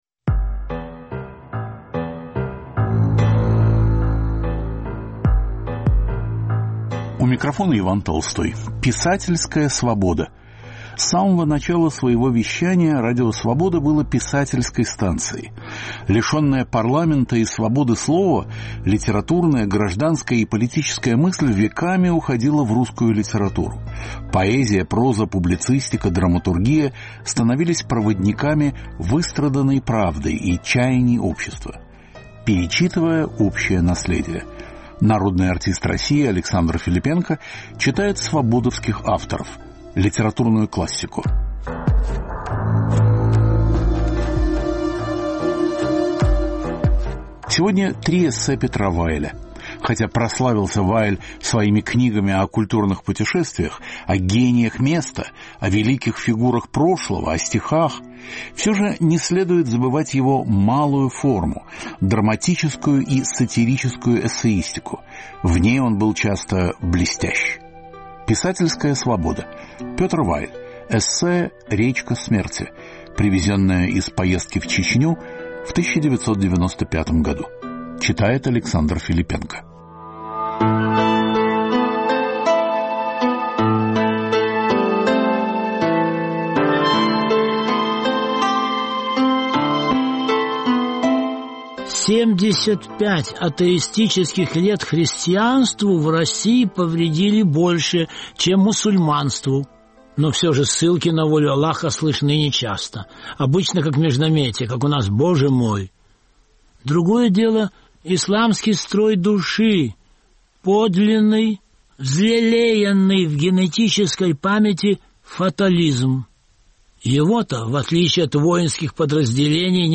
Три очерка Петра Вайля в чтении народного артиста России А. Филиппенко.